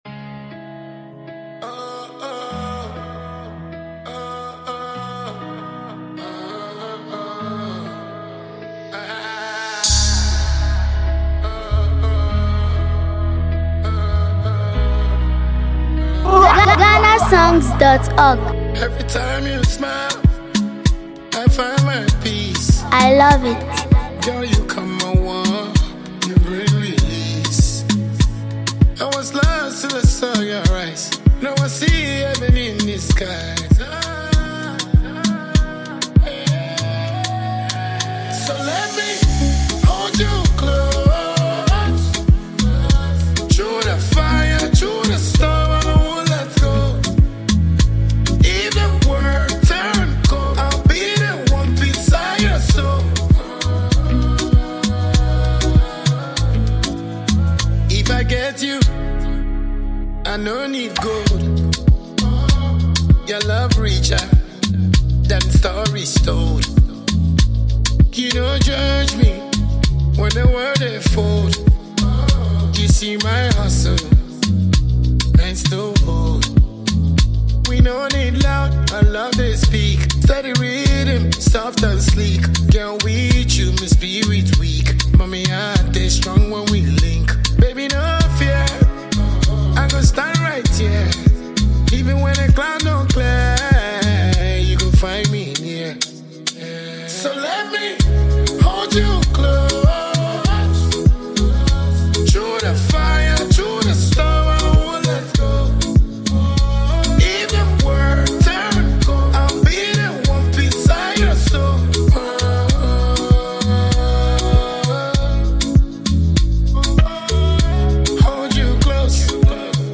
” a love-driven song that speaks about affection
With smooth melodies and heartfelt lyrics